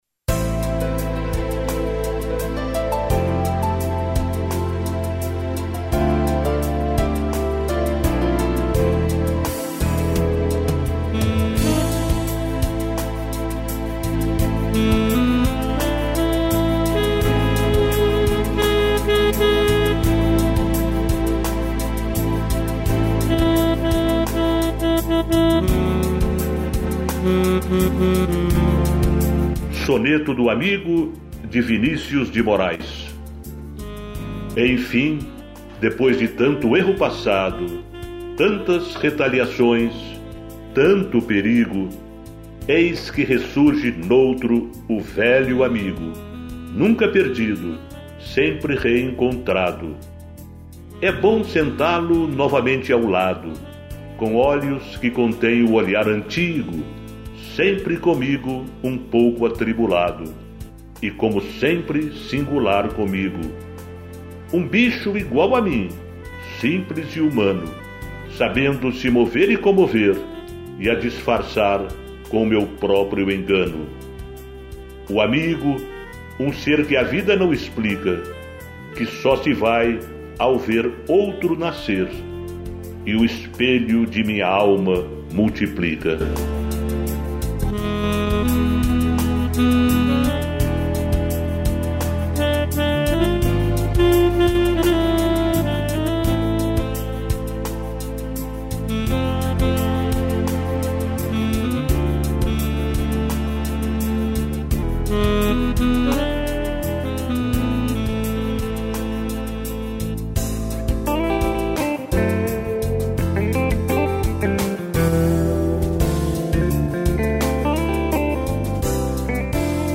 interpretação do texto